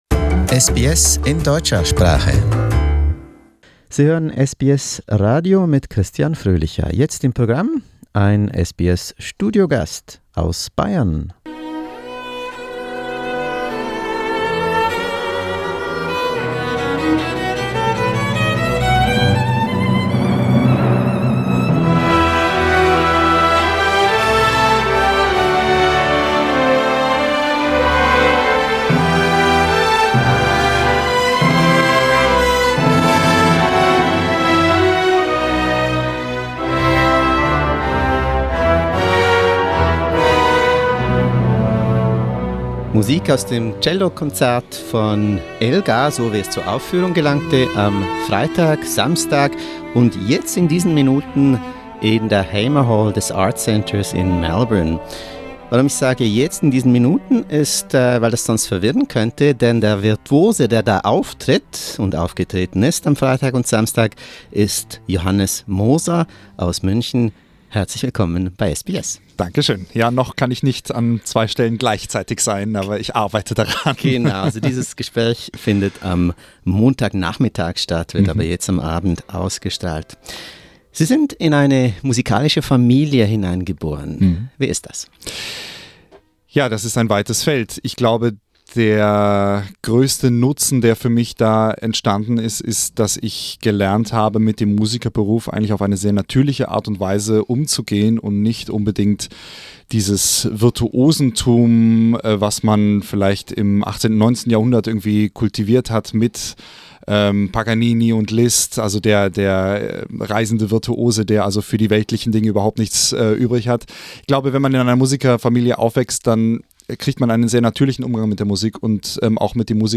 To find out more, listen to a memorable studio interview with Johannes Moser.